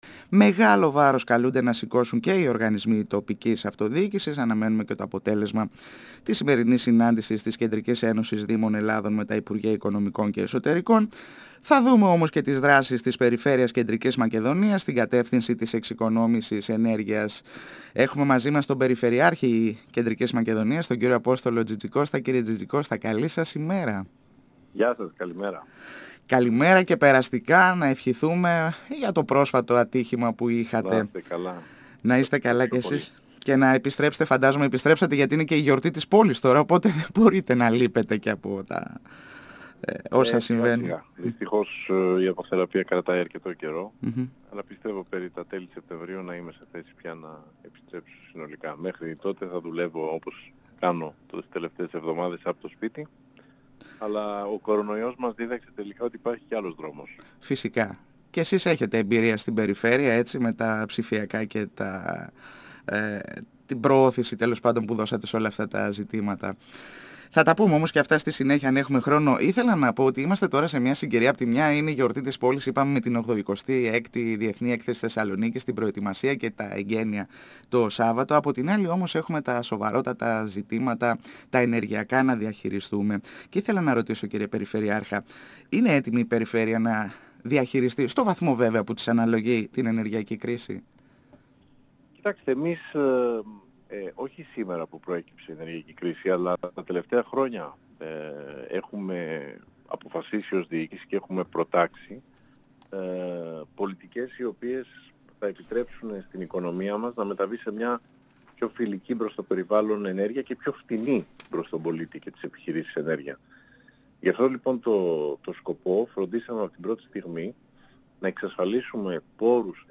Ο περιφερειάρχης Κεντρικής Μακεδονίας Απόστολος Τζιτζικώστας στον 102 FM | 07.09.2022